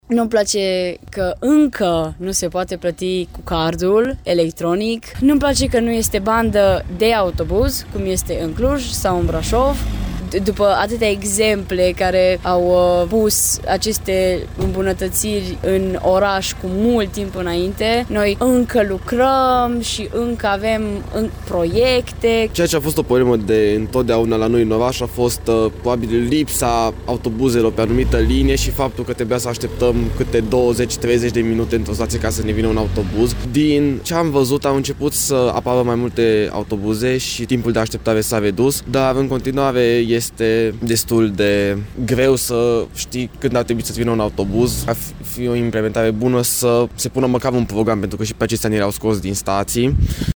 Călătorii spun că se văd unele îmbunătăţiri în sistemul de transport în comun, dar aşteaptă ca acesta să fie modernizat: